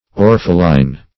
Orpheline \Or"phe*line\, n.